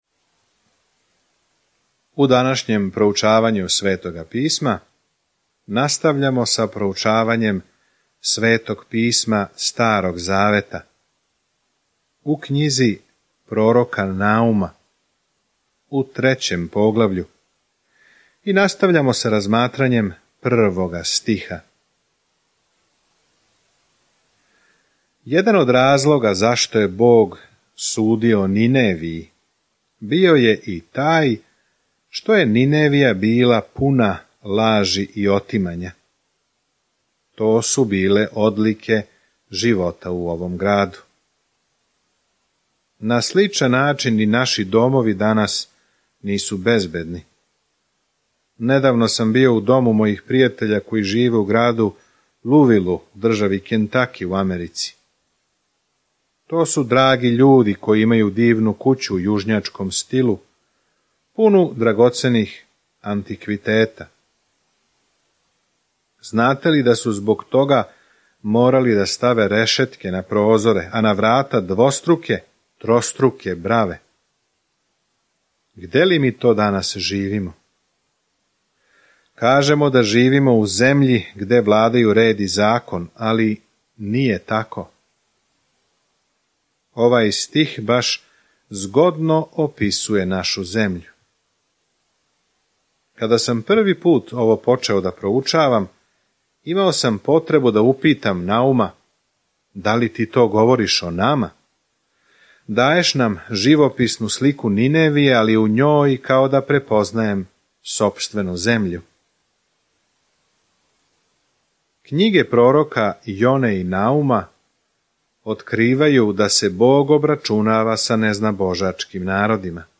Sveto Pismo Knjiga proroka Nauma 3:2-6 Dan 7 Započni ovaj plan Dan 9 O ovom planu Наум, чије име значи утеха, доноси поруку пресуде Божјим непријатељима и доноси и правду и наду Израелу. Свакодневно путујте кроз Наум док слушате аудио студију и читате одабране стихове из Божје речи.